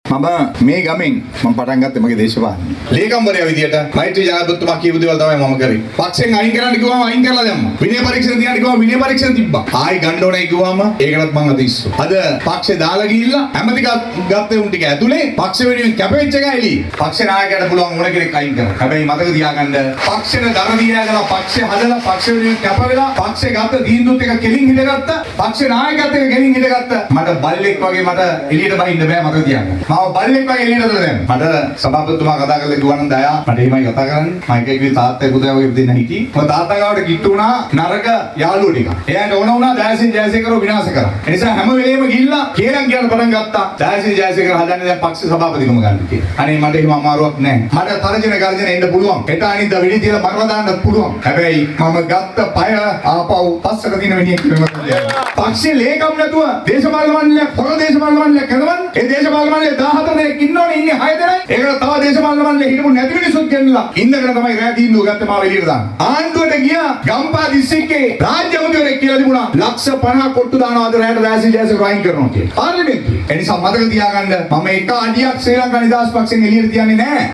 එහිදී කුරුණෑගල පක්ෂ කාර්යාලයේදී එහි පැමිණ සිටි පිරිස අමතමින් මන්ත්‍රීවරයා මේ බව ප්‍රකාශ කළා .